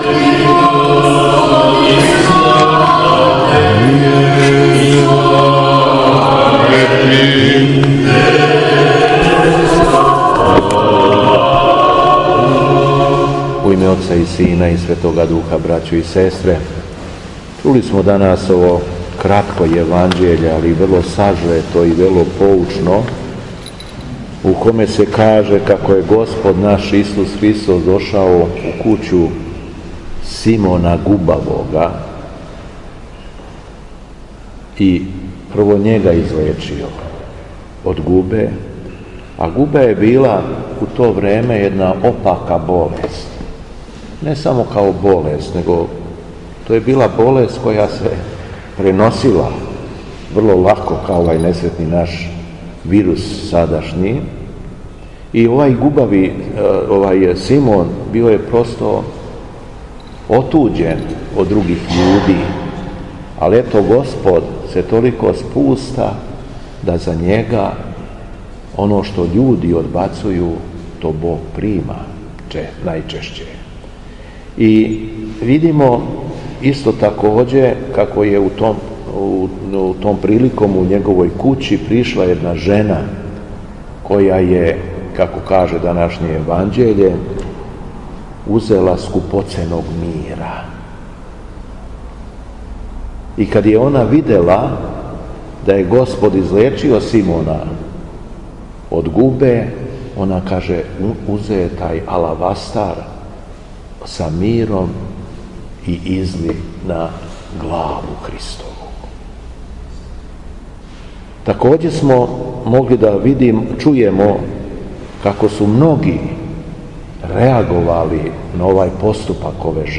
Беседа Његовог Преосвештенства Епископа шумадијског г. Јована
После прочитаних Јевнђелских речи од Светог Апостола и Јеванђелисте Марка о помазању Христовом у Витанији, Преосвећени Владика се обратио сабраном народу беседом: